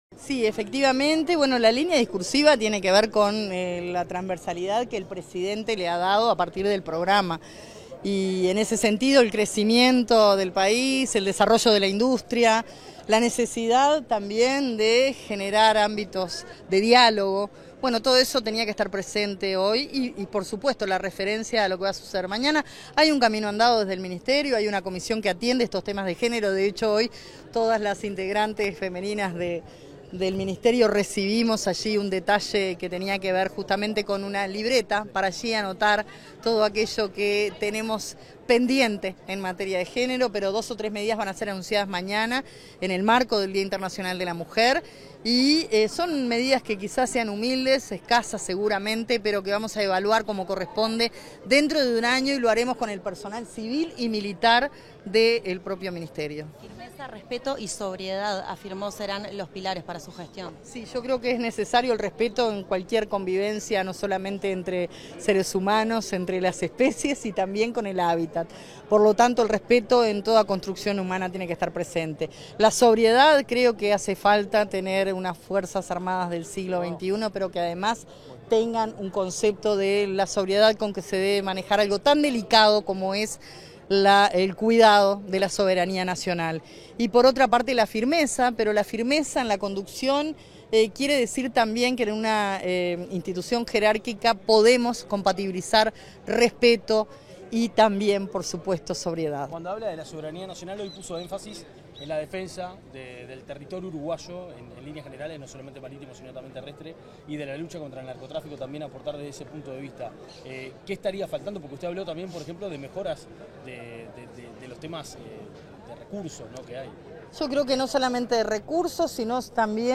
Declaraciones a la prensa de la ministra de Defensa Nacional, Sandra Lazo